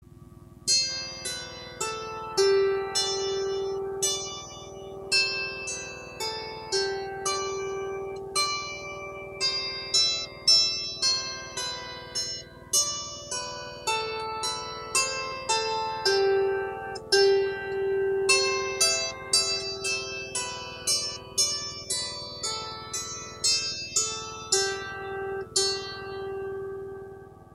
Мелодия для гуслей